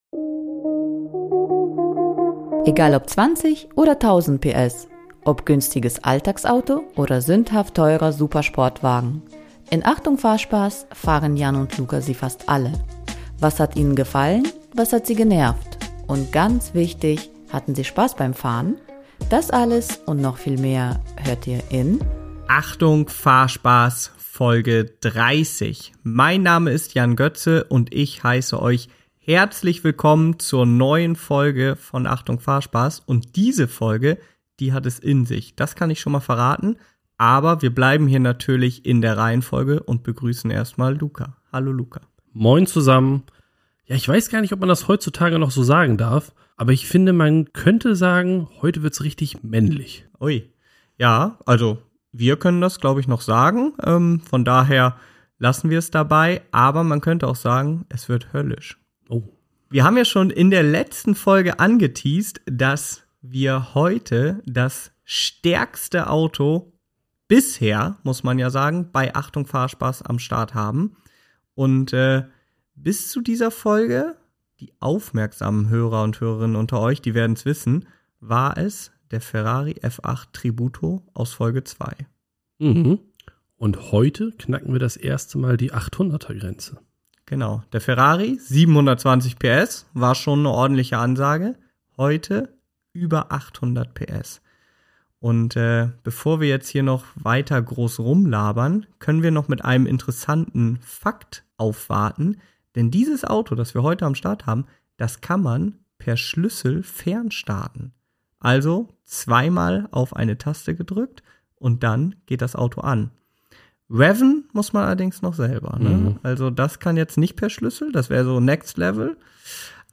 Und wenn euch das alles noch nicht überzeugt hat, dann wird es spätestens der Kompressorsoud tun - versprochen!